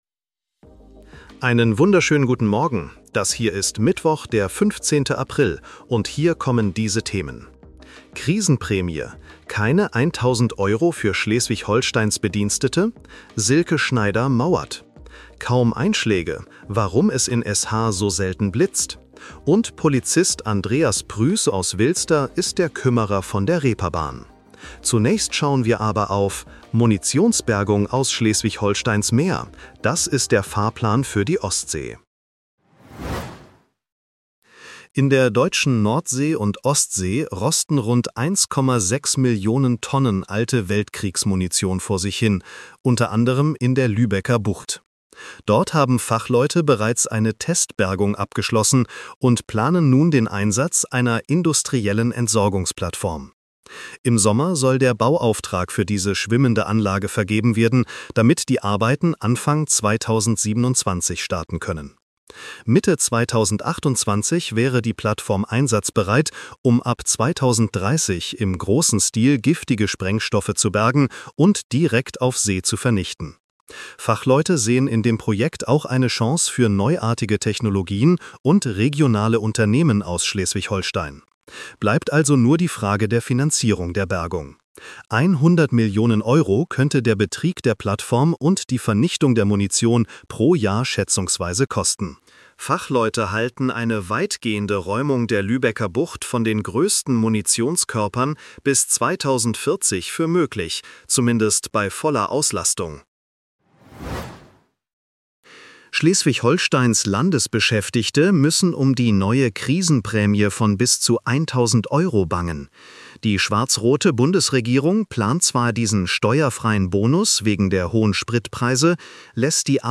Nachrichten-Podcast bekommst Du ab 7:30 Uhr die wichtigsten